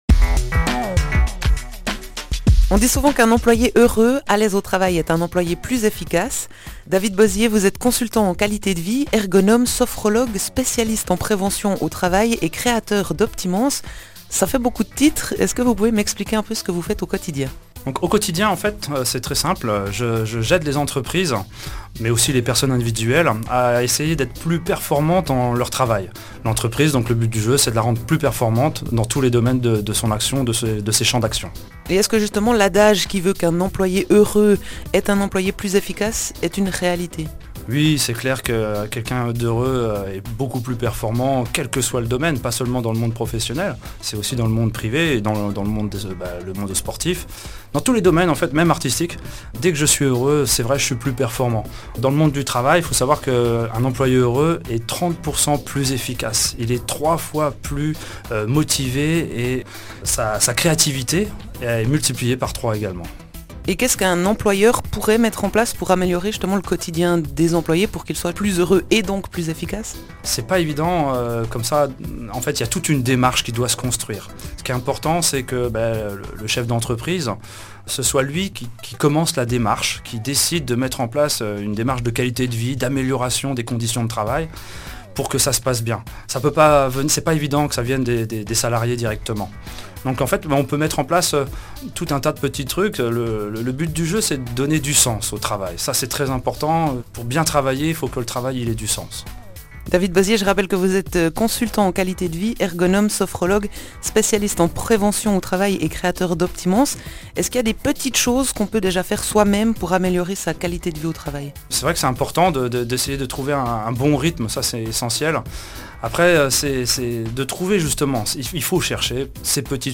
Présentation rapide sur RTN (radio locale) du bien-être et de la performance en entreprise.